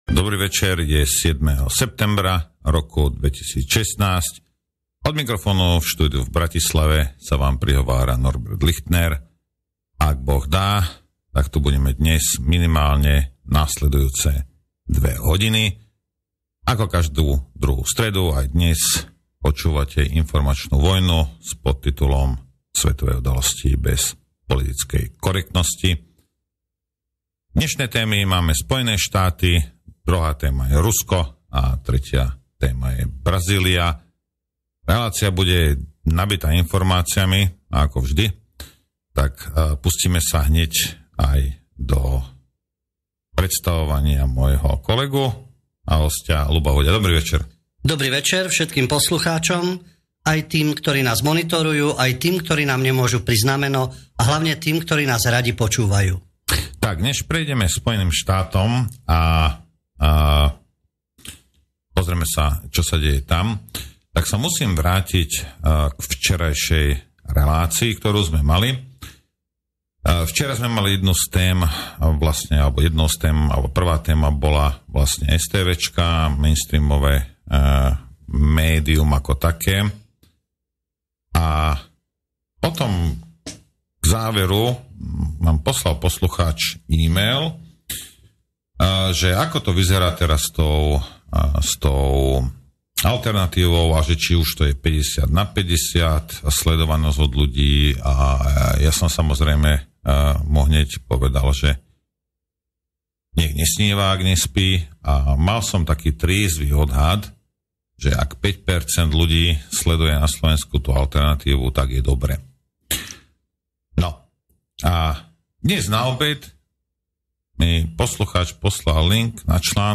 Prevzaté vysielanie z rádia Slobodný vysielač Témy: 1, USA – impérium mení stratégiu a Brzezinski prehodnocuje taktiku… 2, Rusko – opatrenia proti prevratu a konkurenčný svetový poriadok… 3, Brazília – odstavenie prezidentky pravicovým pučom